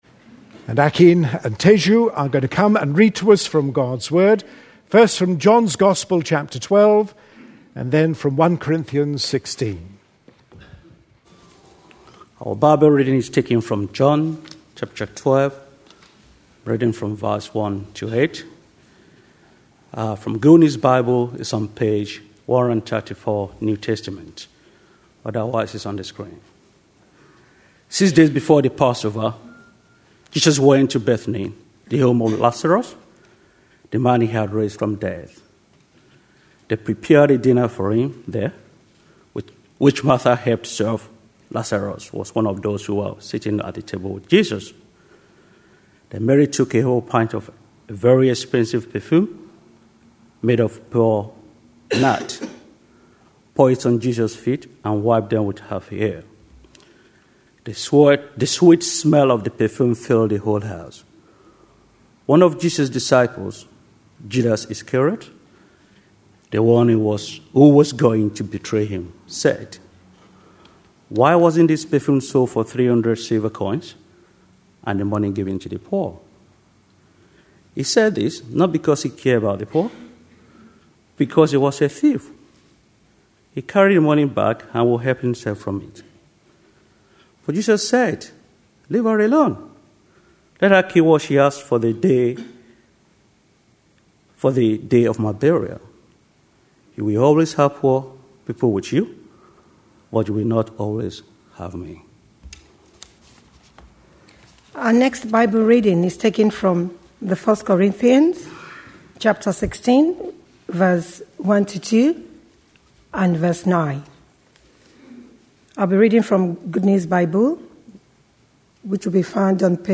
A sermon preached on 13th March, 2011, as part of our A Passion For.... series.